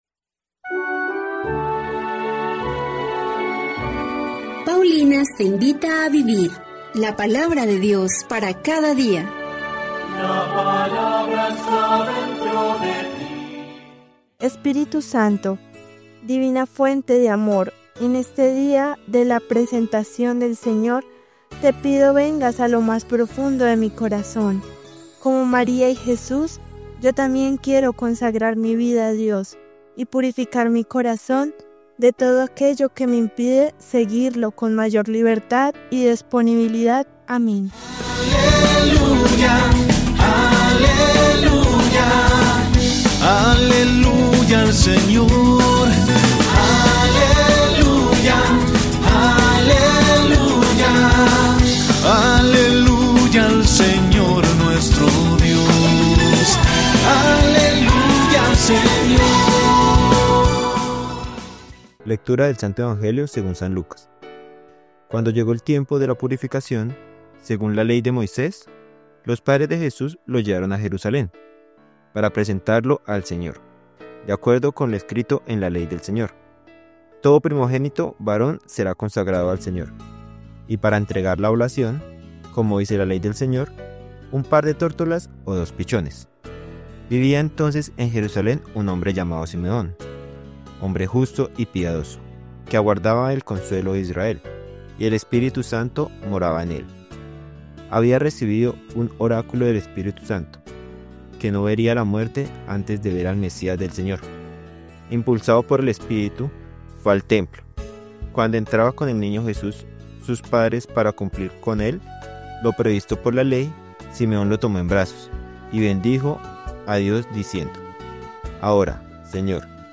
Lectura del libro de Malaquías 3, 1-4